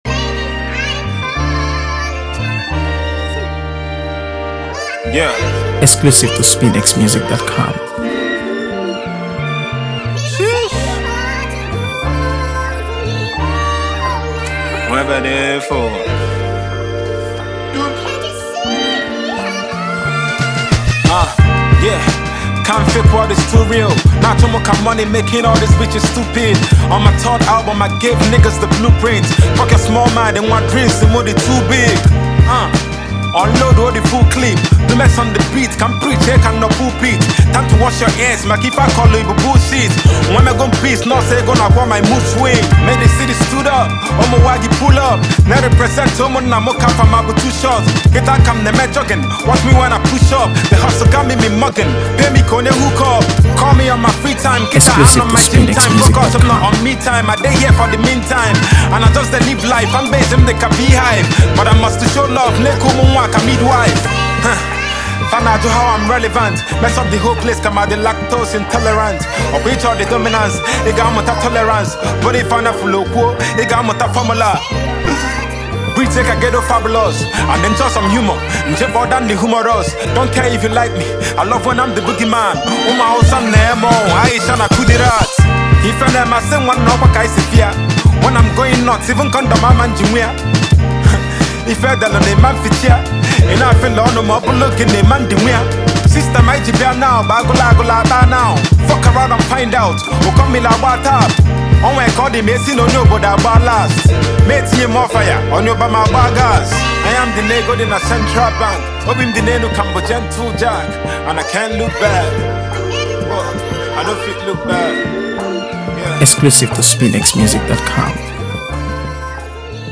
AfroBeats | AfroBeats songs
refreshing vibe